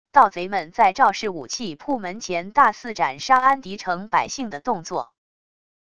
盗贼们在赵氏武器铺门前大肆斩杀安迪城百姓的动作wav音频